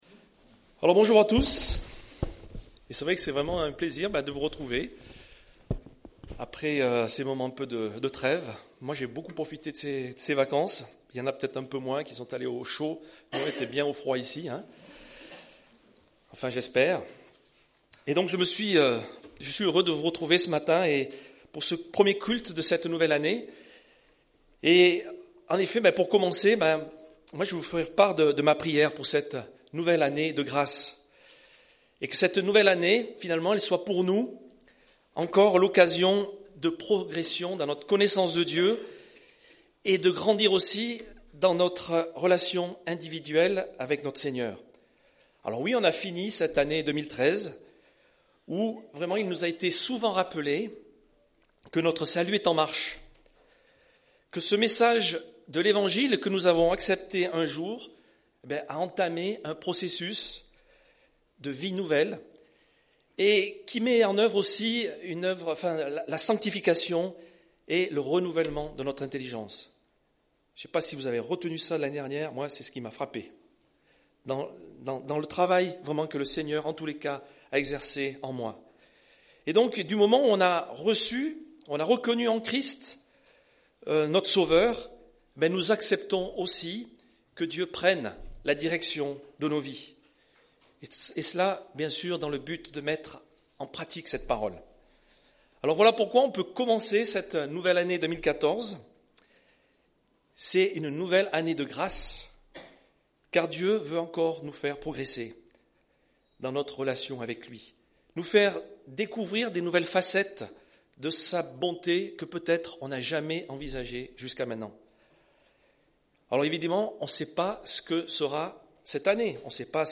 Culte du 05 janvier